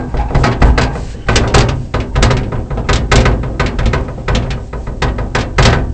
rr3-assets/files/.depot/audio/sfx/car_damage/impact_bodyflap_3.wav
impact_bodyflap_3.wav